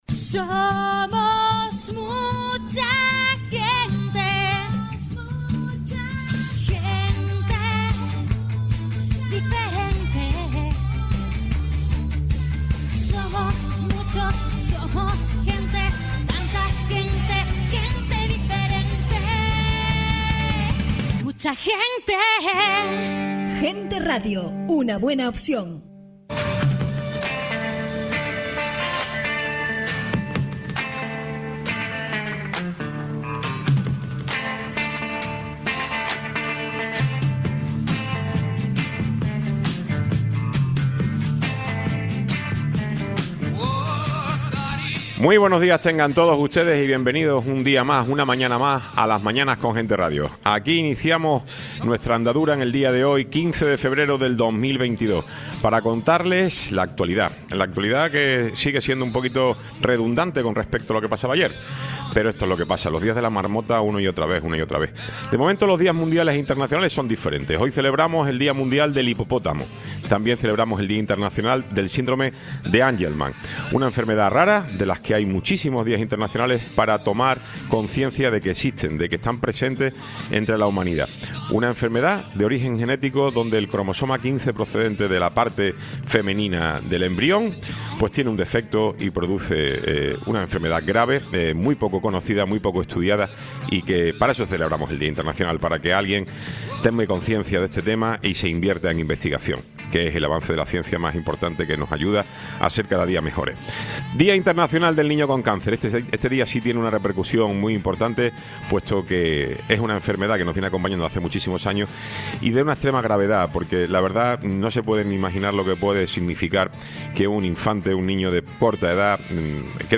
Tiempo de entrevista
Tertulia